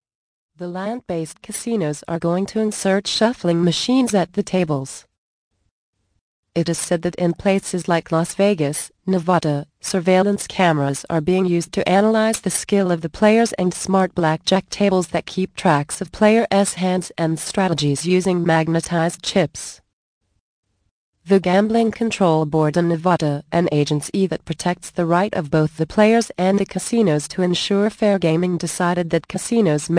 Thanks for your interest in this audio book.